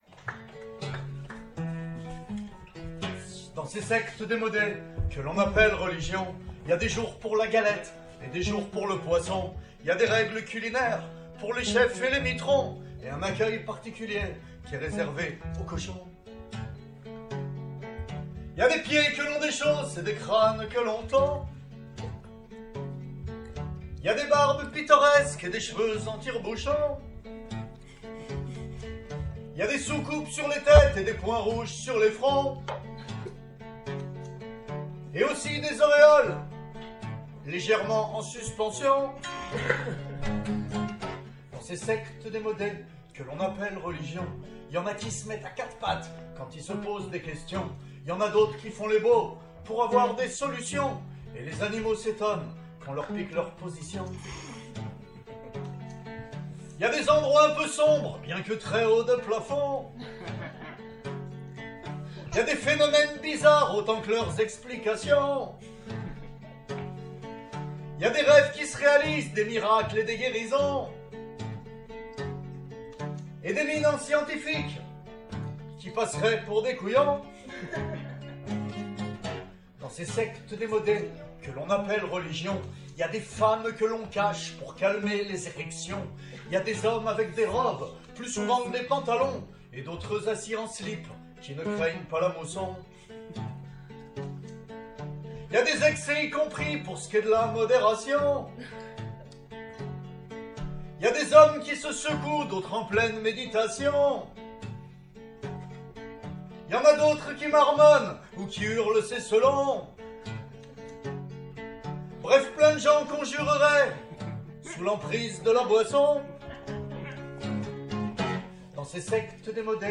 Avantage : comme chanteur, vous venez avec votre guitare, vous avez un micro, un ampli guitare si vous souhaitez, un régisseur son et lumière – what else?